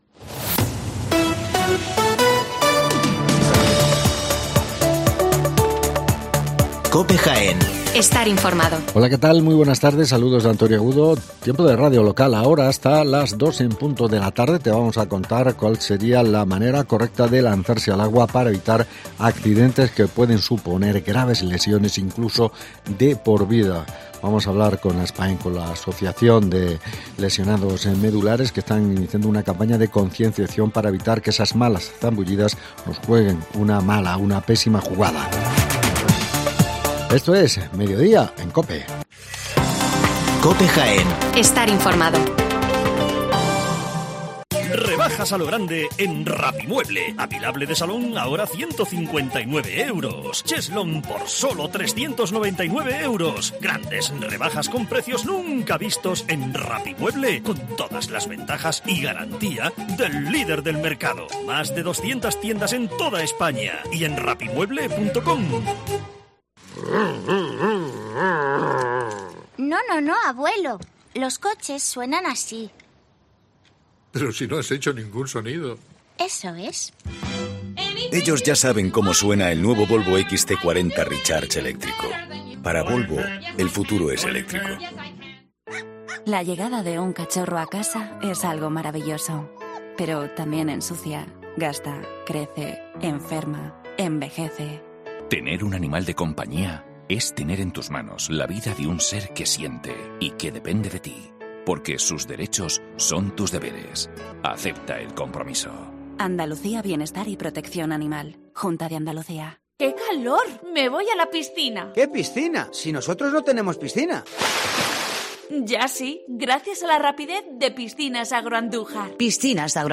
AUDIO: ASPAYM Jaén desarrolla a lo largo del mes de agosto una acción de sensibilización frente a las malas zambullidas en las piscinas...